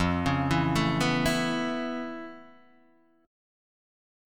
Aadd9/F chord {1 4 2 2 0 0} chord